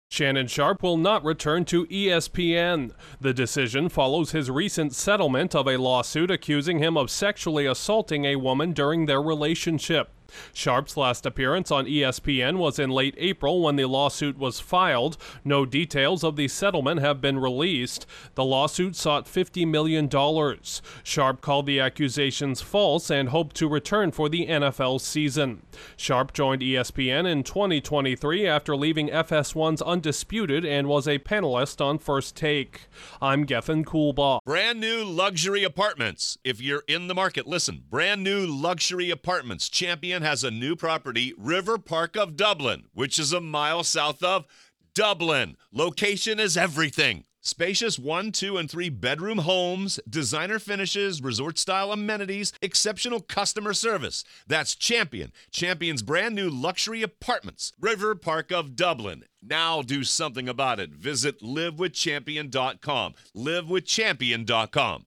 A Hall of Fame tight end turned high-profile football analyst is out at ESPN. Correspondent